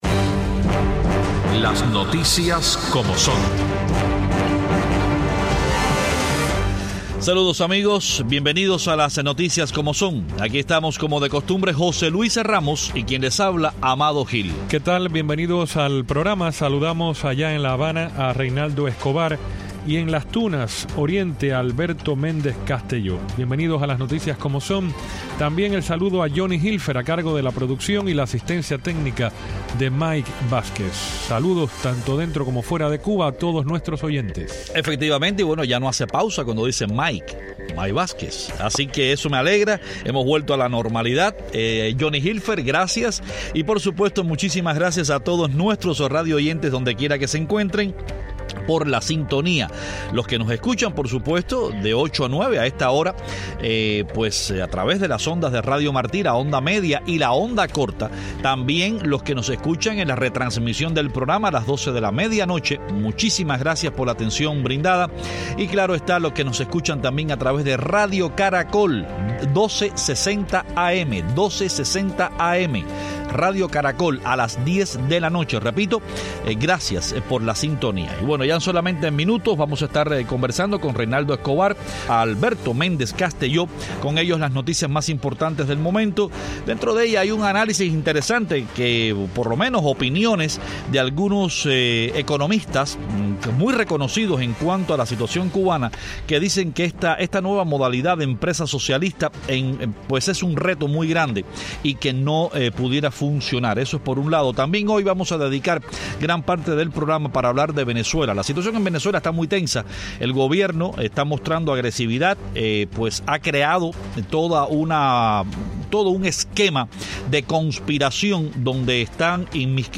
Los periodistas cubanos